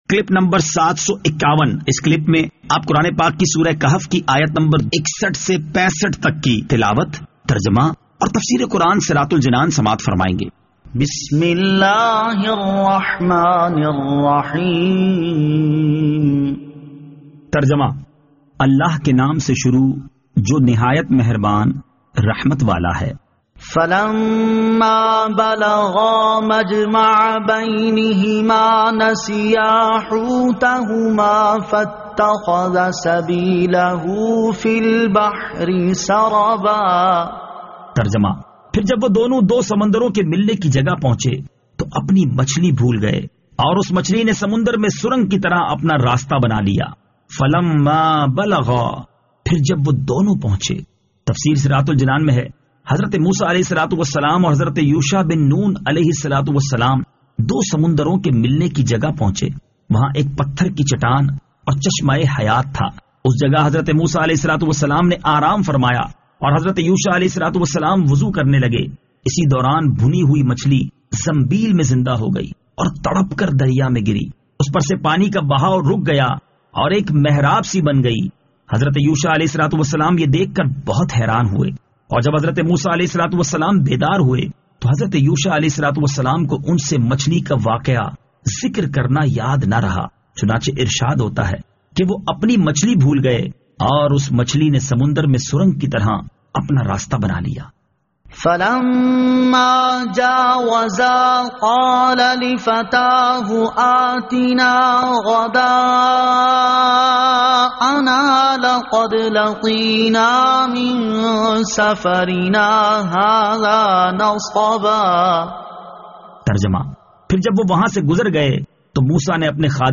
Surah Al-Kahf Ayat 61 To 65 Tilawat , Tarjama , Tafseer